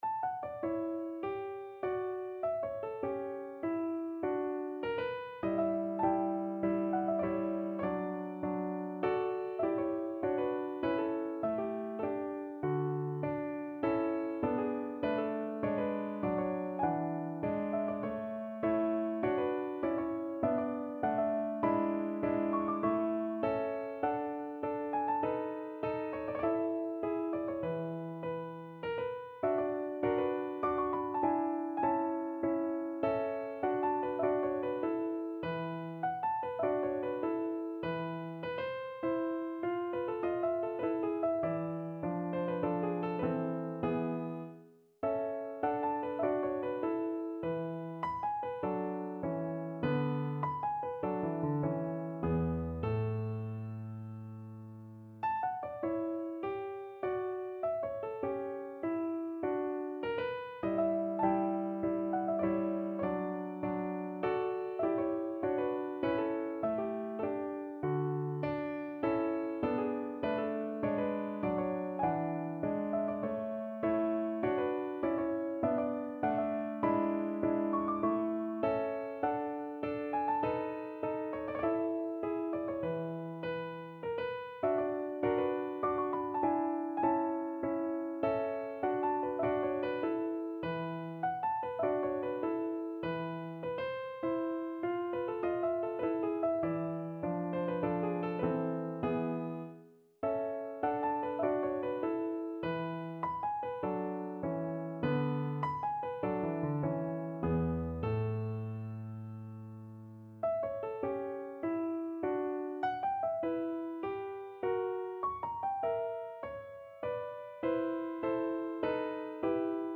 No parts available for this pieces as it is for solo piano.
2/2 (View more 2/2 Music)
Cantabile andantino
Piano  (View more Intermediate Piano Music)
Classical (View more Classical Piano Music)